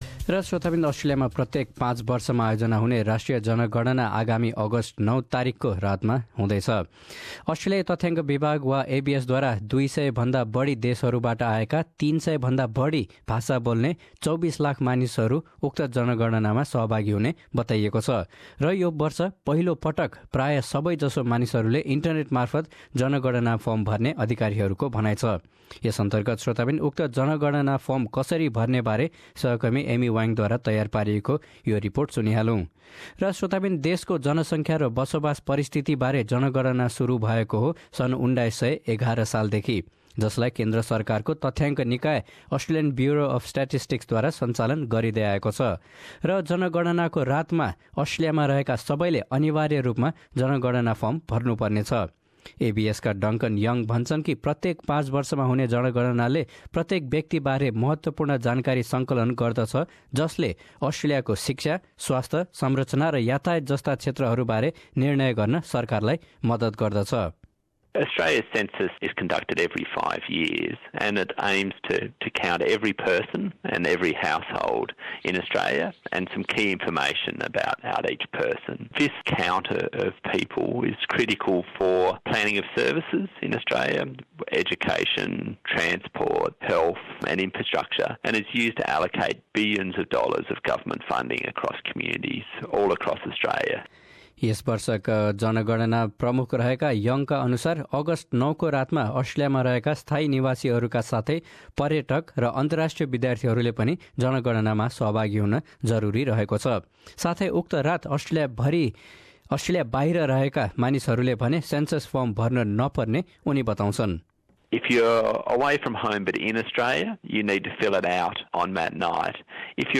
प्रस्तुत छ यसबारे थप रिपोर्ट।